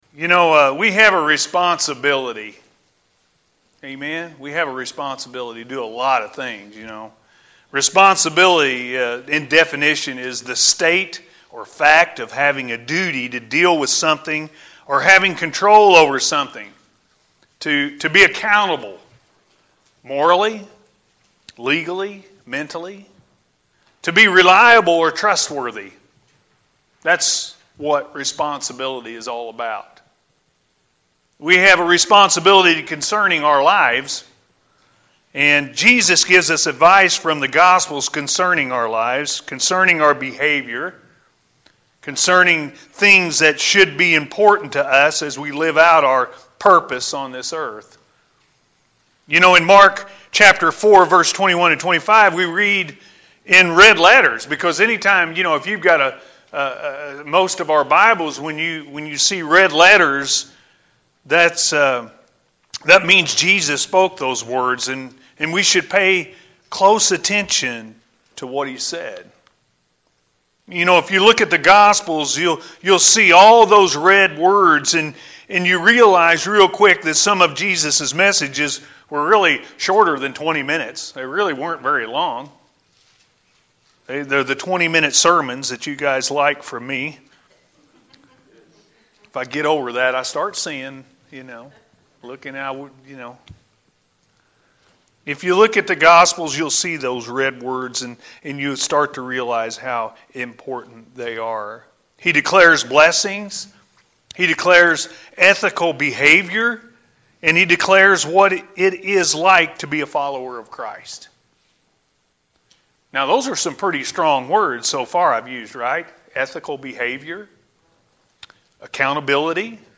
Our Responsibility To The Gospel-A.M. Service